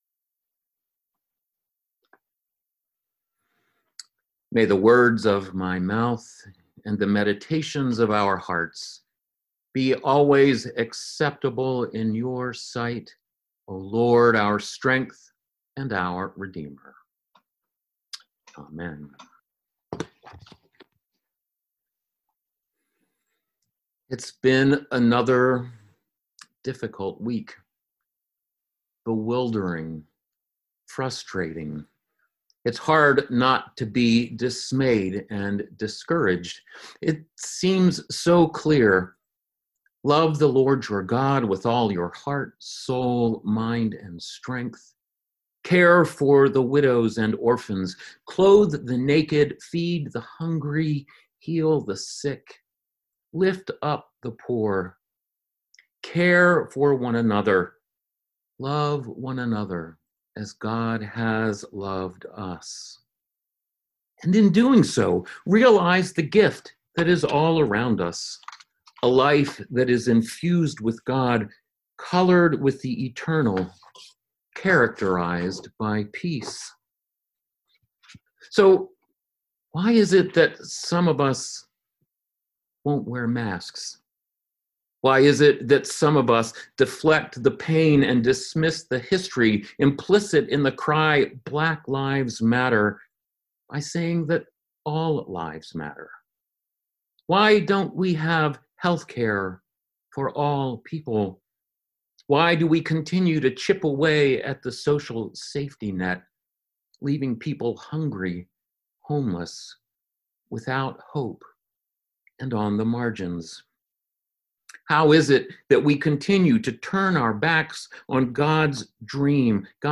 The sermon is built around the texts assigned for Proper 10 in year A of the Revised Common Lectionary.